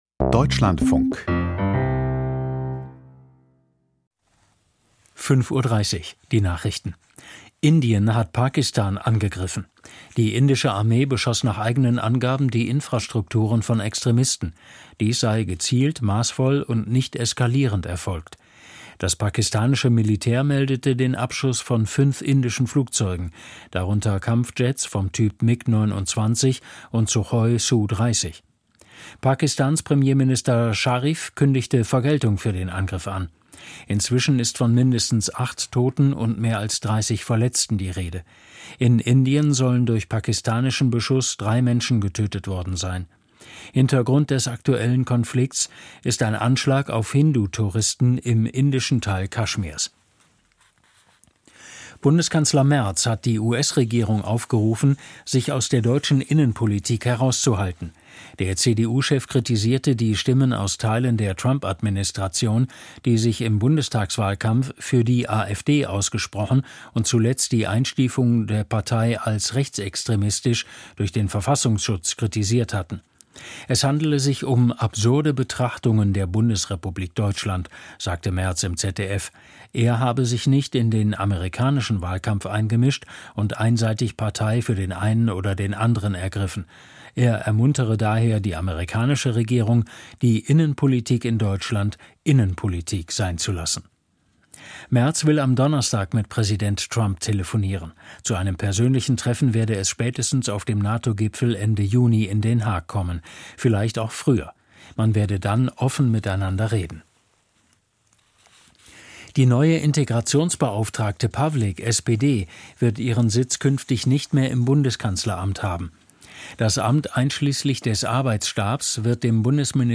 Die Deutschlandfunk-Nachrichten vom 07.05.2025, 05:30 Uhr